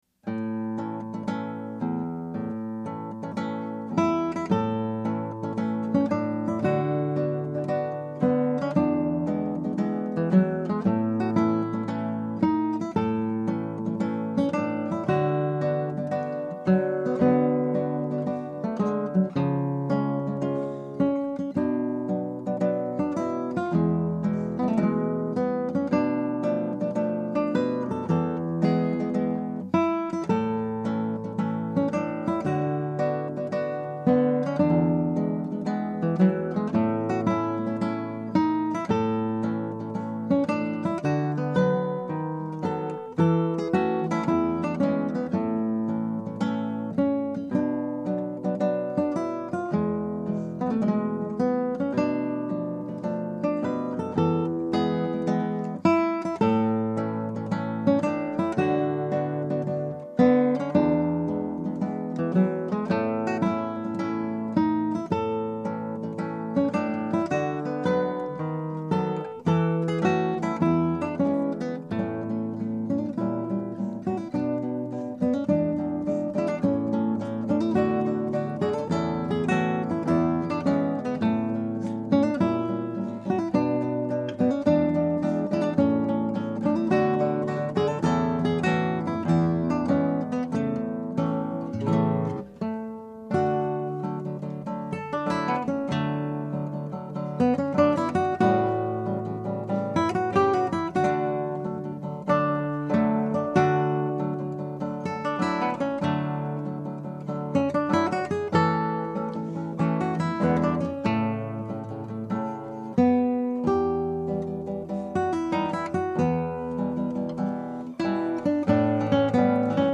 Scraps from the Operas arranged for Two Guitars
Scrap 1: Marziale.
And there's a kind of sameness to the three sections which we didn't overcome.
On the other hand, maybe we come closest to sounding like amateur guitarists from Holland's time on this one.